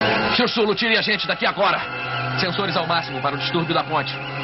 Kirk ordena retirada imediata em